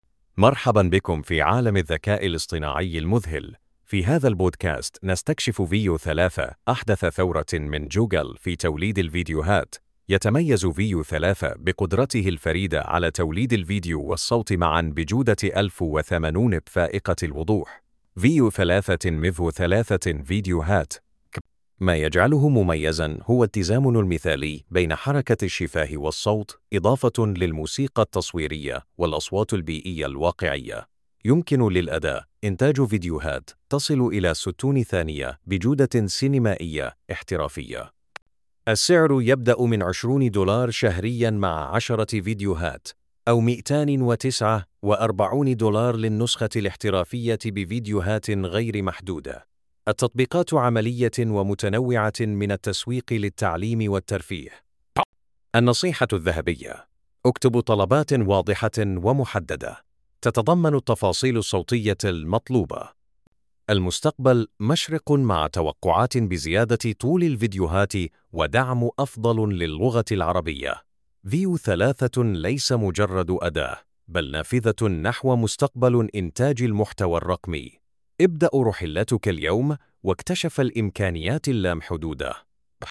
بودكاست Veo 3: استمع للدليل الشامل
صوت ذكوري احترافي